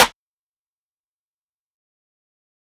SCOTT_STORCH_snare_rim_real.wav